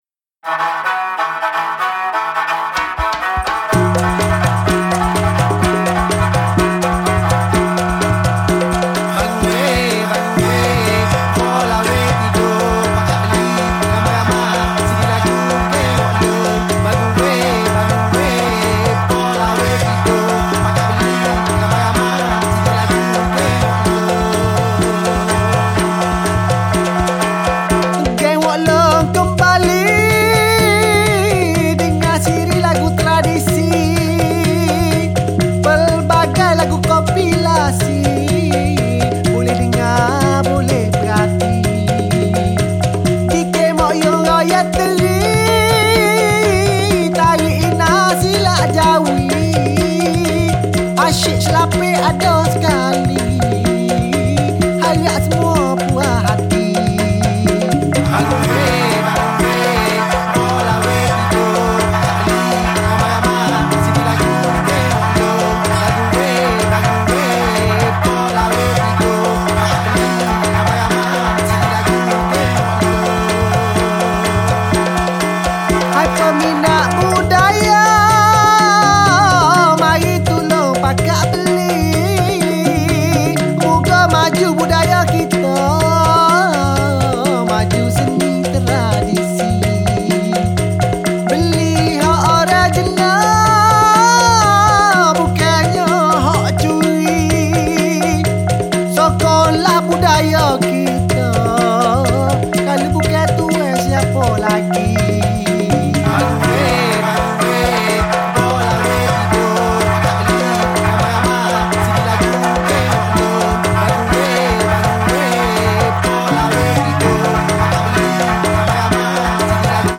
サイケデリックという言葉では生ぬるく思えてしまうような、太古の響きをも携えた極上の民族音楽です！